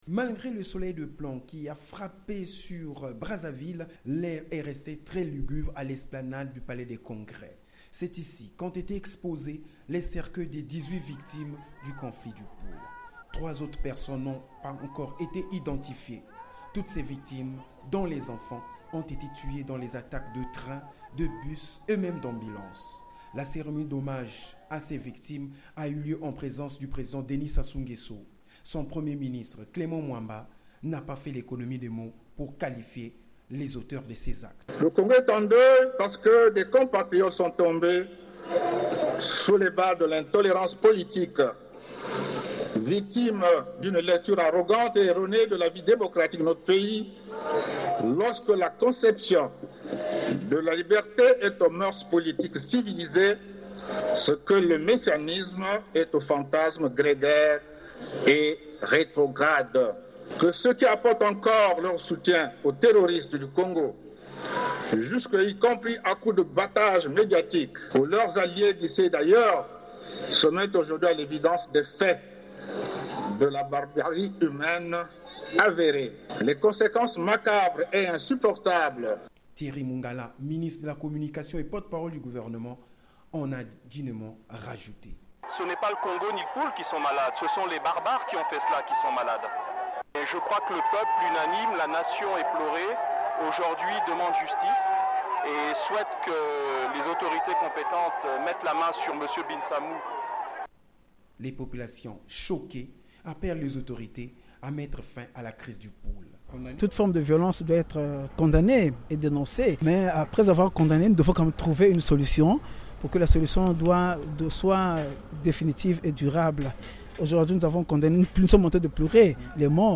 Brèves Sonores